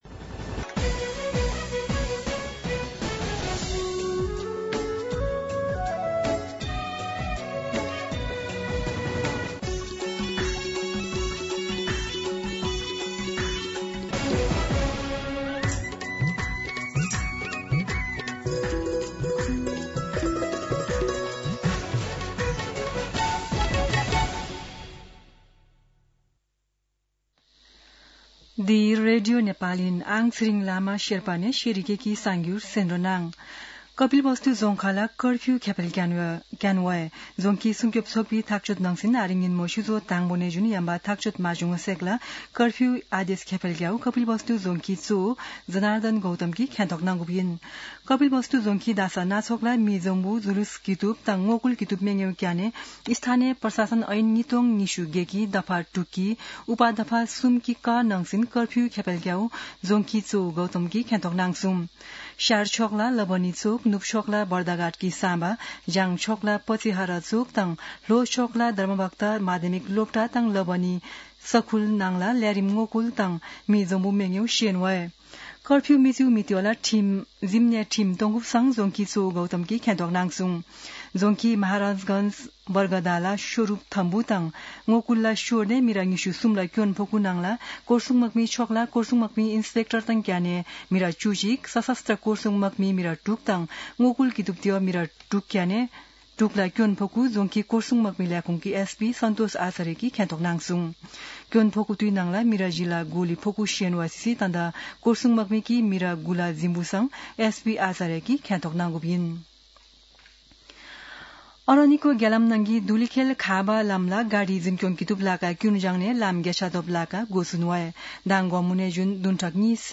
शेर्पा भाषाको समाचार : ८ चैत , २०८२